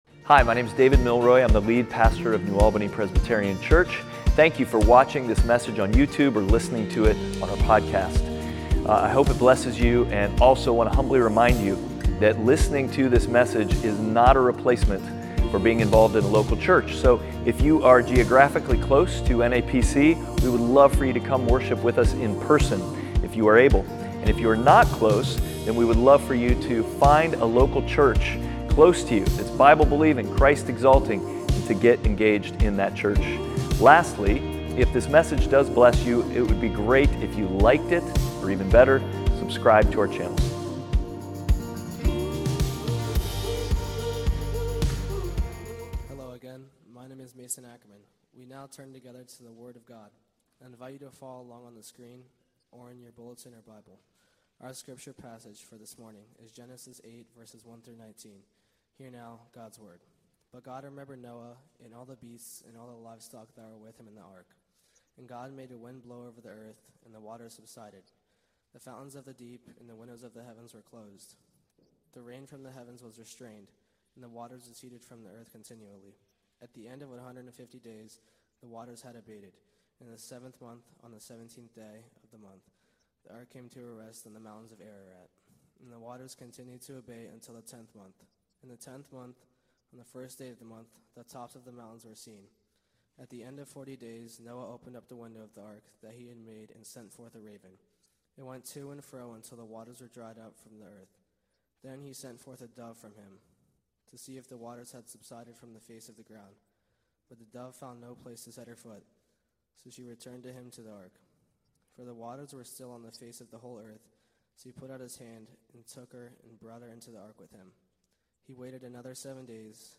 From Beginning to Babel Passage: Genesis 8:1-19 Service Type: Sunday Worship « From Beginning To Babel